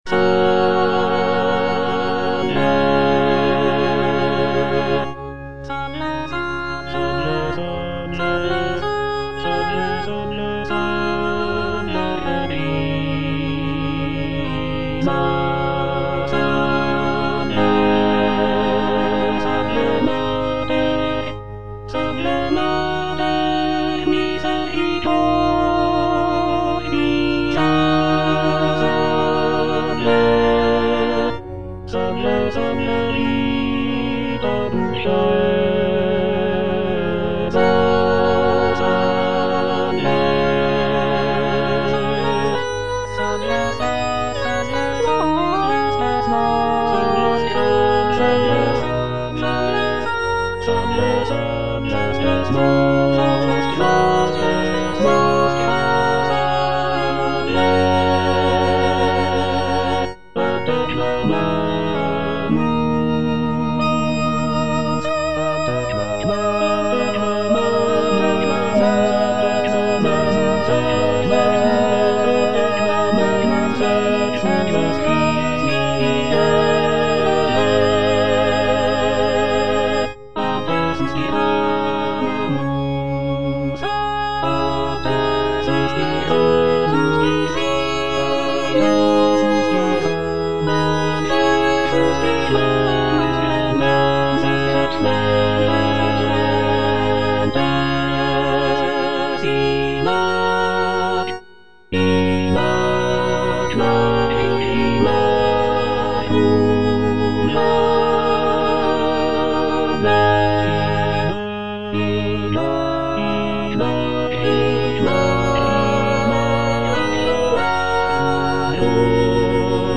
G.F. SANCES - SALVE, REGINA (A = 415 Hz) (All voices) Ads stop: auto-stop
"Salve, Regina (A = 415 Hz)" is a sacred choral work composed by Giovanni Felice Sances in the 17th century.
The work features rich harmonies, expressive melodies, and intricate vocal lines, showcasing Sances' skill as a composer of sacred music.